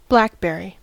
Ääntäminen
US : IPA : /ˈblækbɛɹi/ UK : IPA : /ˈblækbəɹi/ IPA : /ˈblækbɹi/